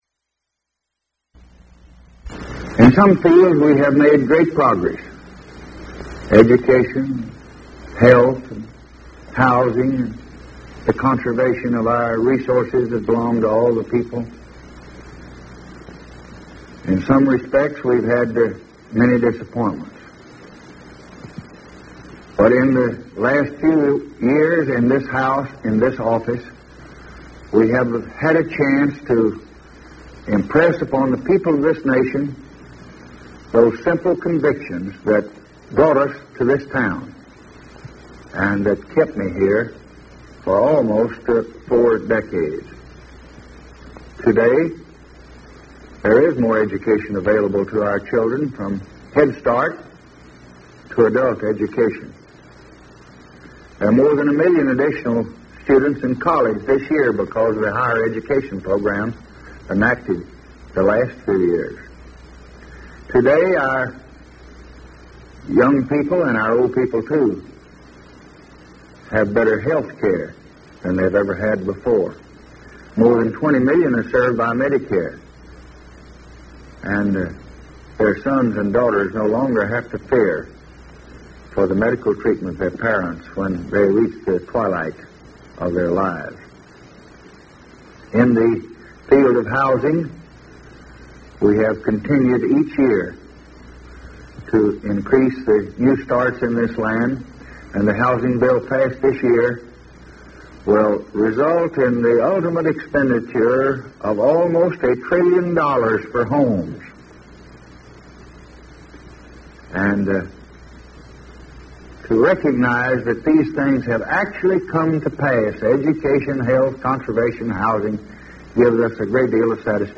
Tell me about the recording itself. Presidents United States Material Type Sound recordings Language English Extent 00:29:30 Venue Note Originally recorded by the Navy Photographic Center for the film "The President's House."